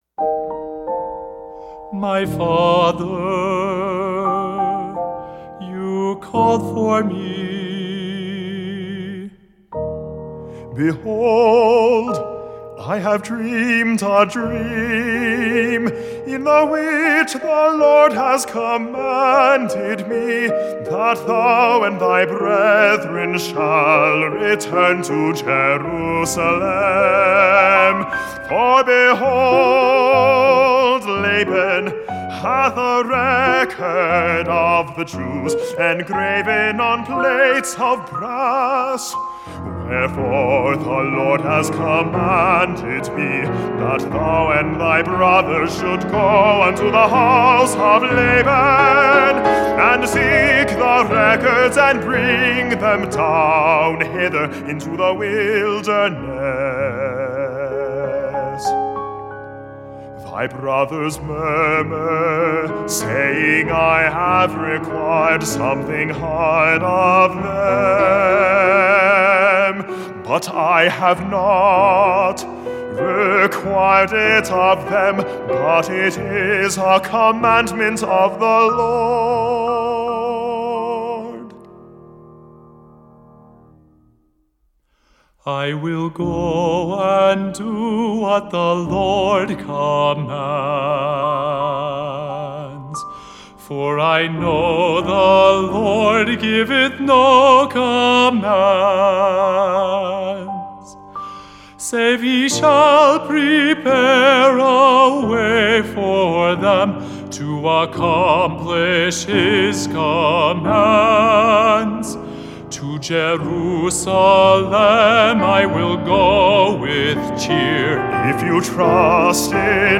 Piano OR Fl, Ob, Cl, Bn, Hn, Tr, Perc, Hp, Pno, and Str
Duet (Nephi and Lehi)